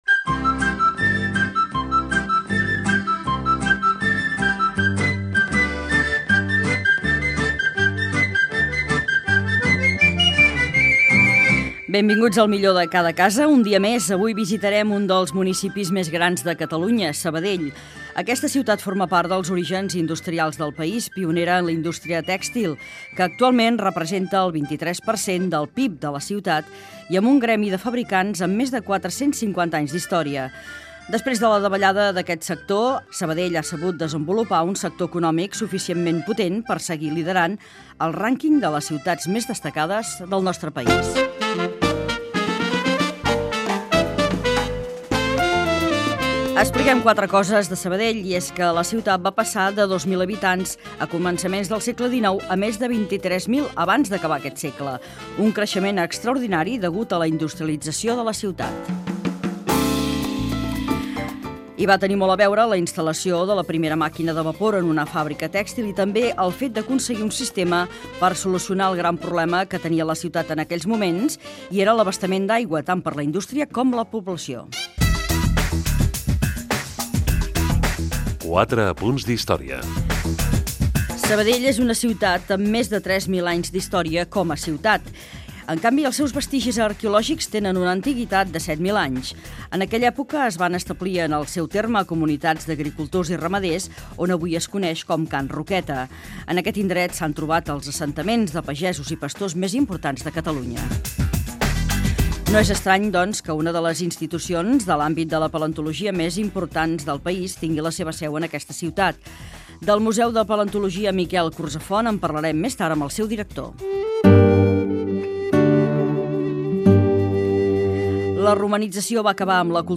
Inici, presentació , "Quatre apunts d'Història" i sumari del programa dedicat a la ciutat de Sabadell. Fragment d'una entrevista a l'alcalde Manel Bustos.
Entreteniment